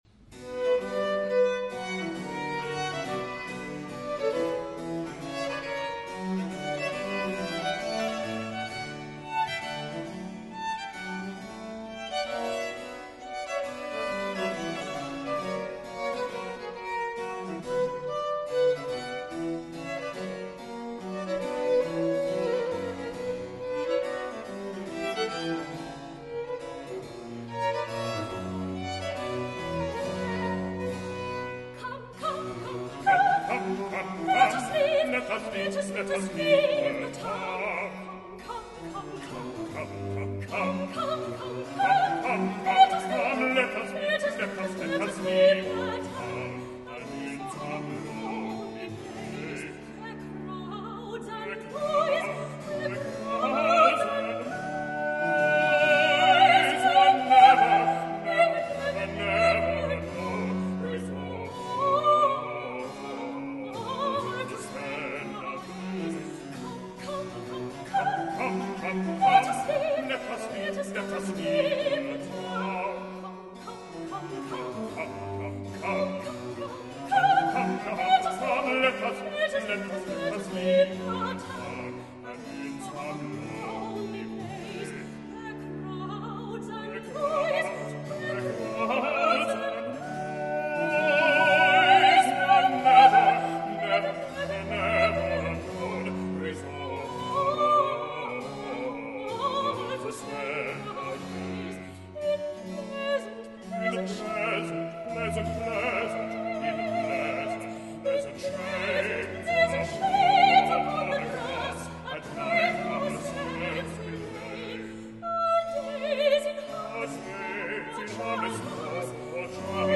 06 - The Fairy Queen, semi-opera, Z. 629- Act 1.
Duet.